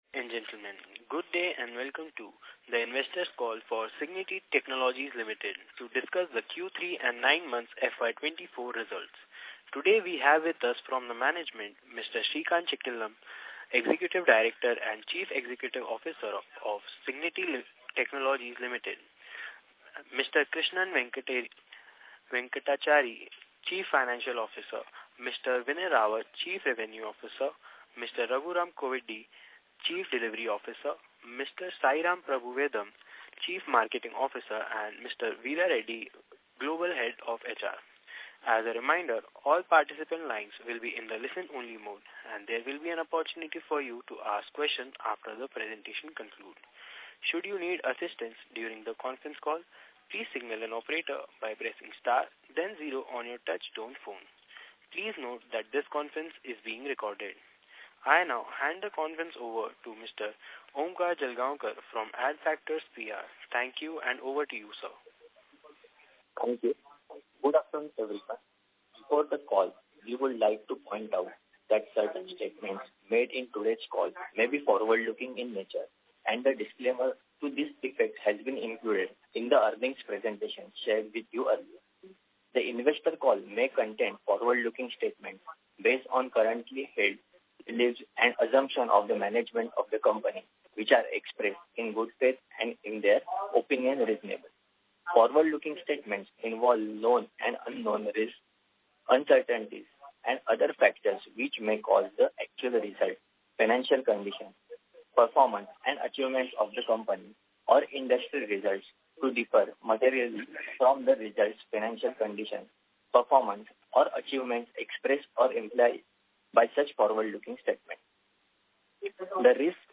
Cigniti-Technologies-Ltd-Q3FY24-Earning-concall-Transcript.mp3